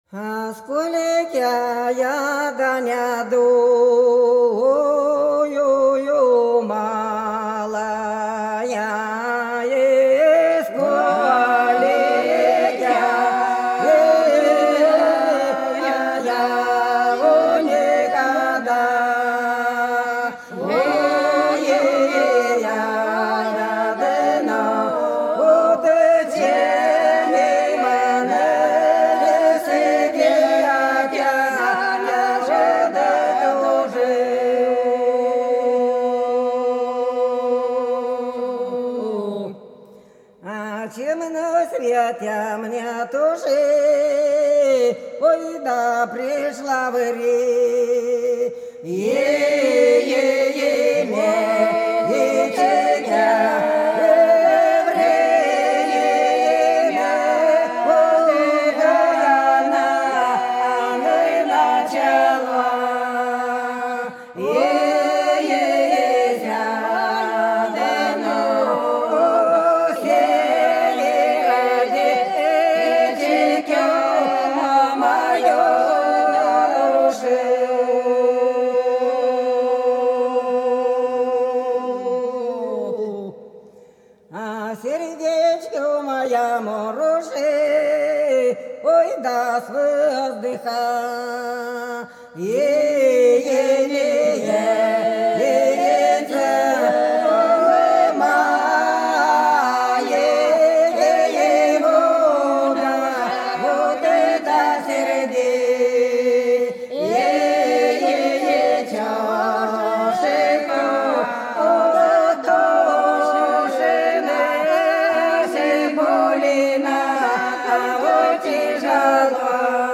Белгородские поля (Поют народные исполнители села Прудки Красногвардейского района Белгородской области Сколько я не думала - протяжная